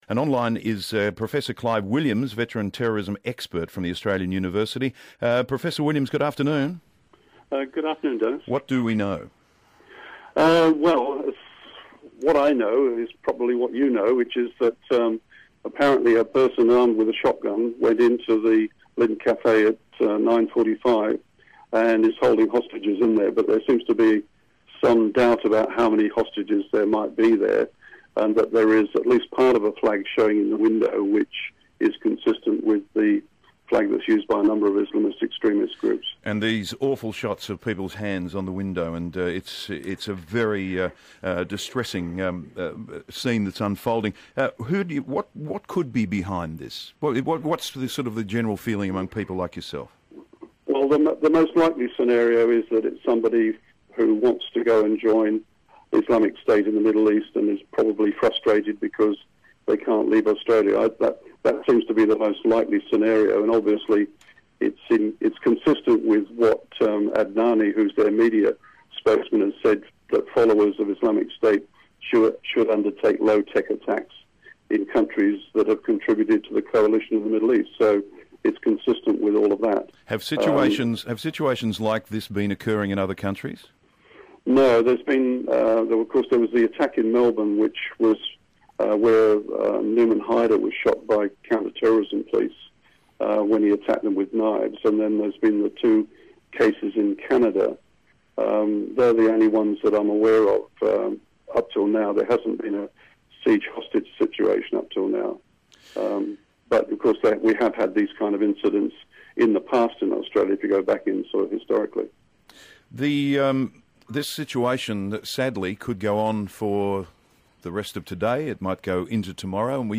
Terrorism expert speaks